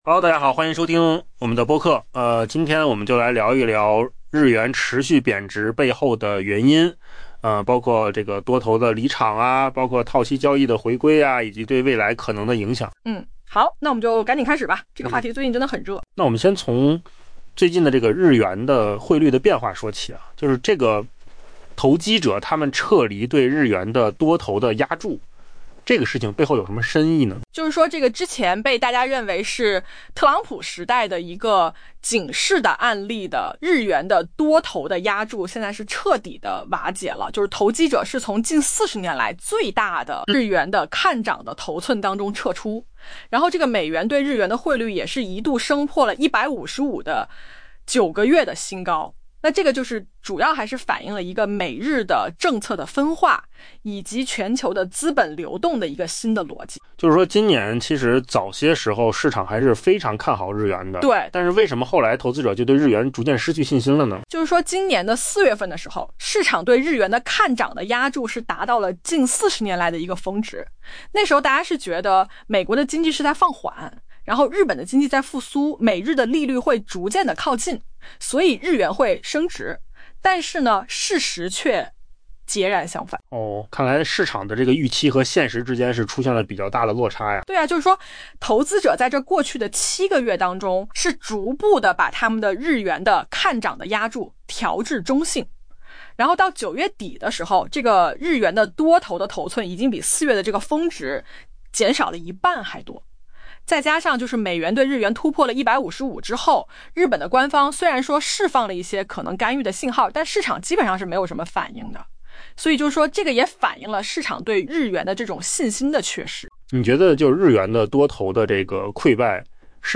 AI 播客：换个方式听新闻 下载 mp3 音频由扣子空间生成 投资者曾押注创纪录规模的资金，认为日元会升值，以期从日本长期期待的经济复苏中获利，同时押注美国经济放缓。